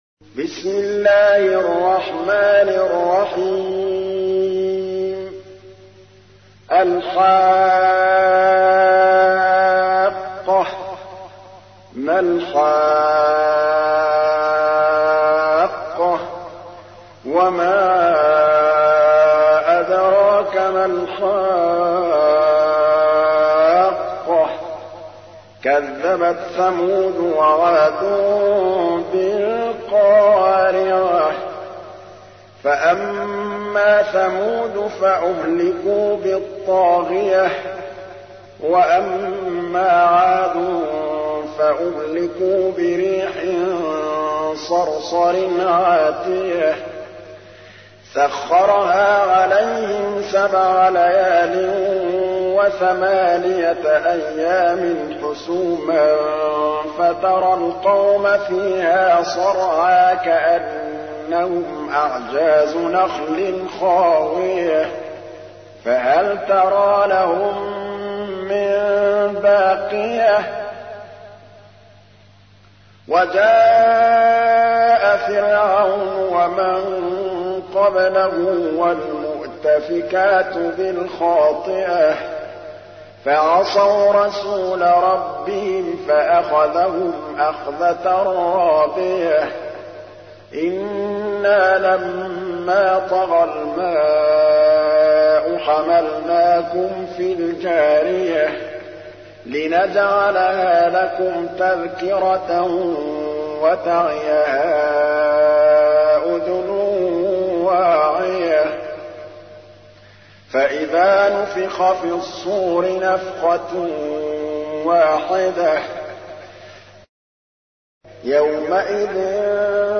تحميل : 69. سورة الحاقة / القارئ محمود الطبلاوي / القرآن الكريم / موقع يا حسين